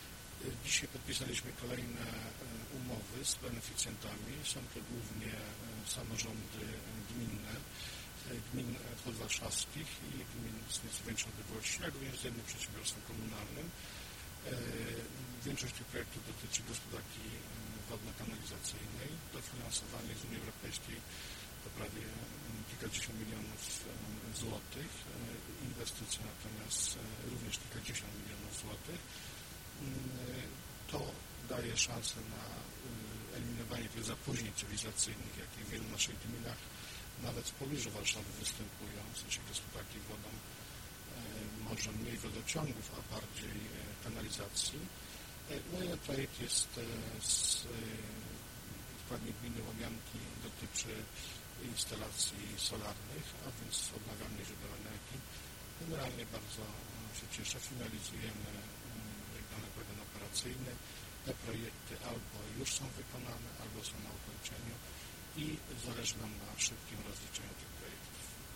Wypowiedź marszałka województwa mazowieckiego, Adama Struzika